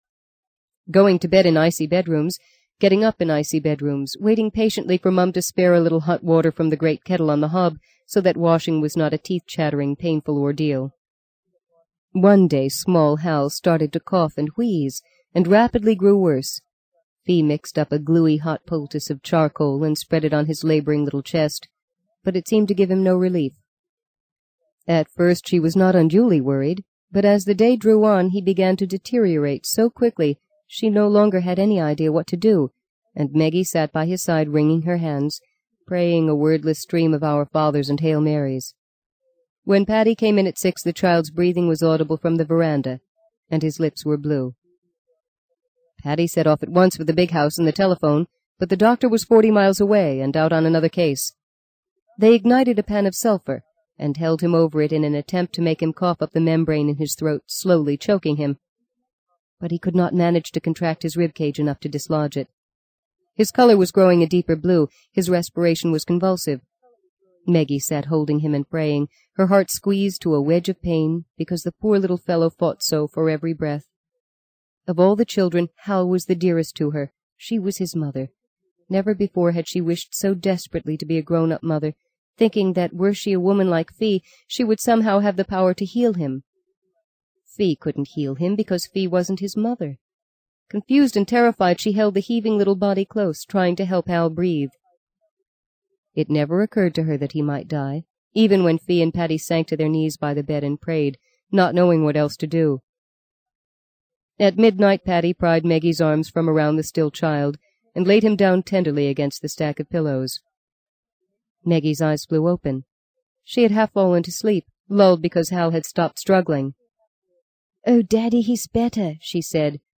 在线英语听力室【荆棘鸟】第六章 10的听力文件下载,荆棘鸟—双语有声读物—听力教程—英语听力—在线英语听力室